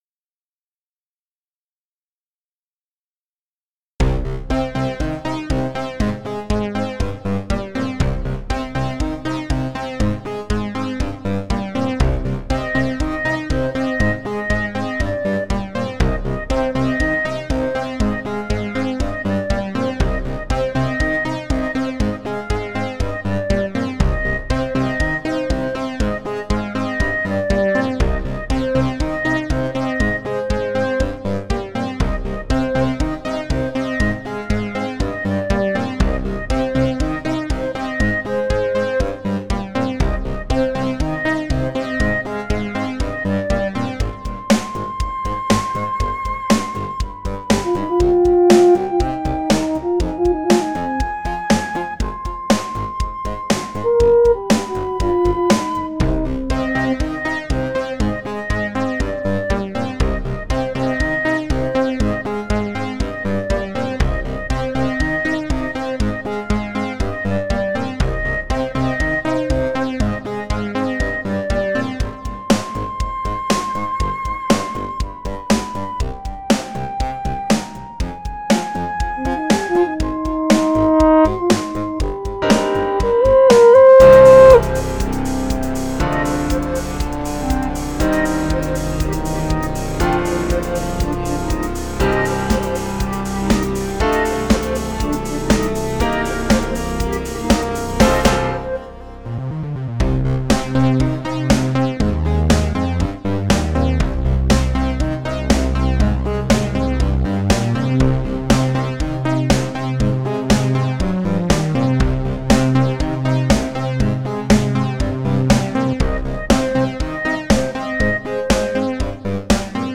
Ich habe hier zwei Klassiker des Synthpop ausgesucht.